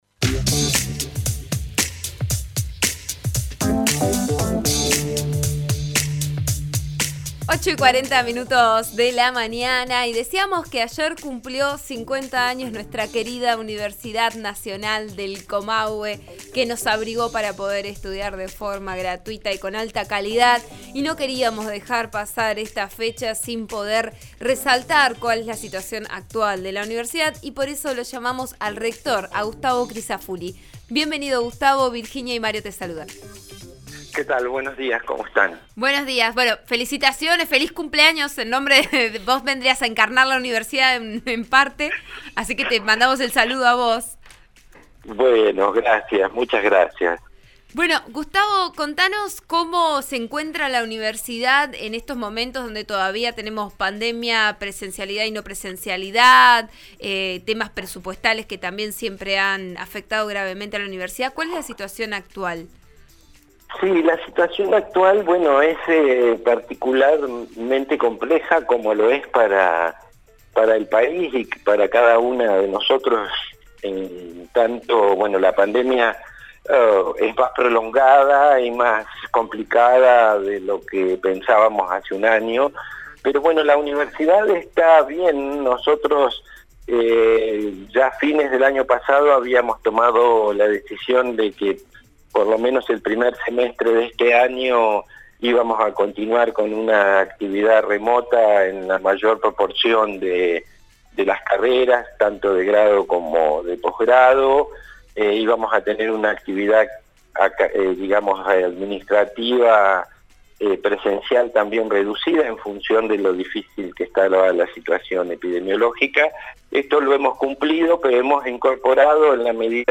Crisafulli conversó con «Vos A Diario» (RN RADIO 89.3) por los 50 años de la UNC y abordó distintos aspectos.